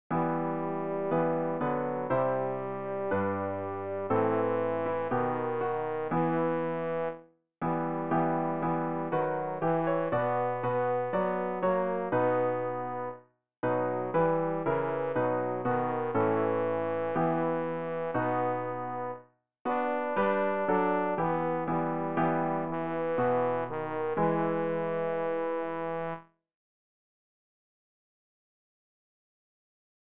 rg-789-wach-auf-tenor.mp3